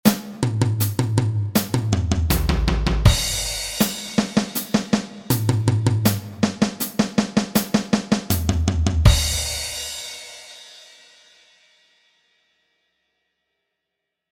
Drum Fills #1
Drum Fills #1 Tempo 80.mp3
drum_fills_1_tempo_80.mp3